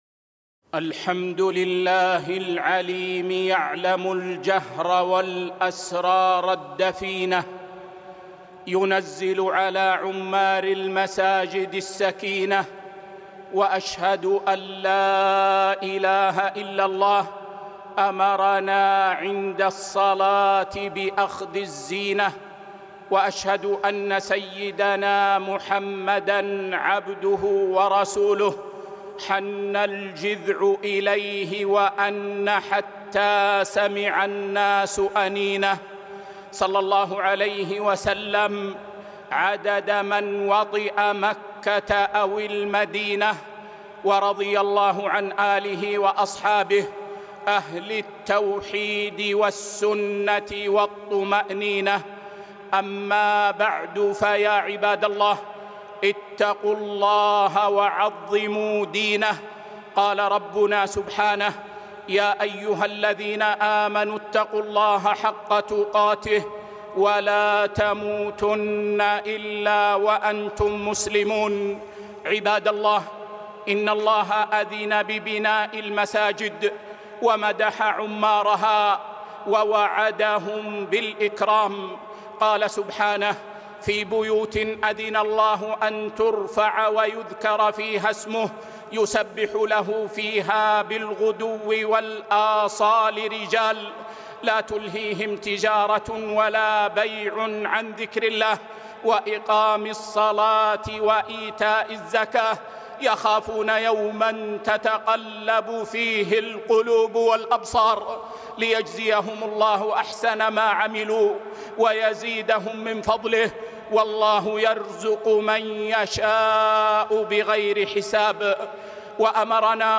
خطبة - ( الله أحق أن يتجمل له )